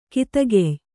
♪ kitagey